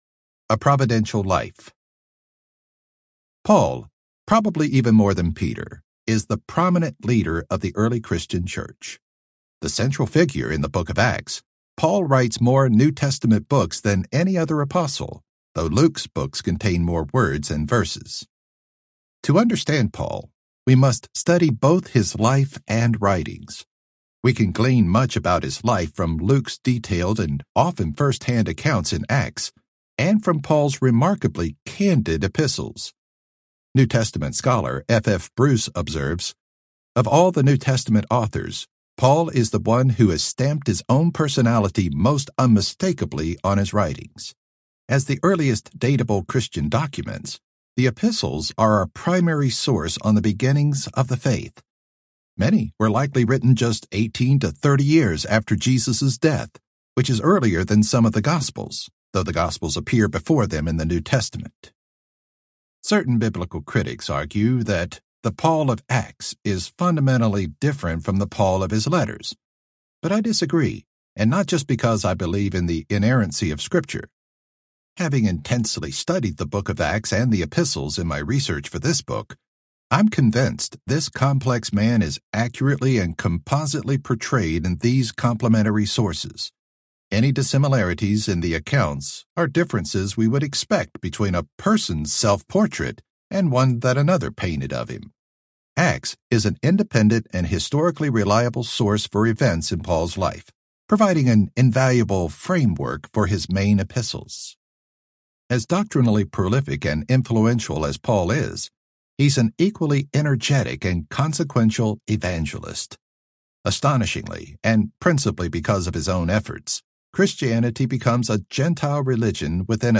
Jesus Is Risen Audiobook
Narrator